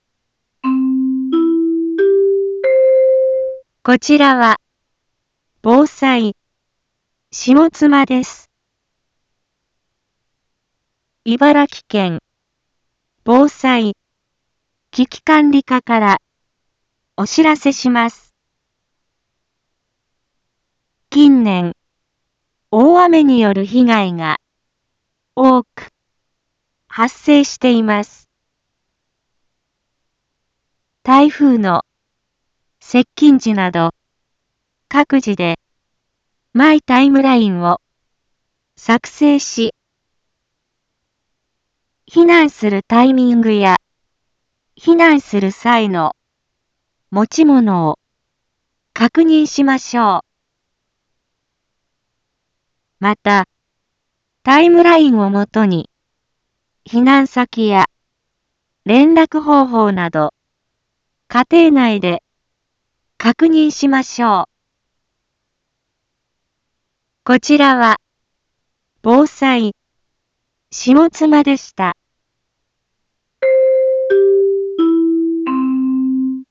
一般放送情報
Back Home 一般放送情報 音声放送 再生 一般放送情報 登録日時：2023-10-27 10:01:20 タイトル：洪水における注意点について インフォメーション：こちらは、防災、下妻です。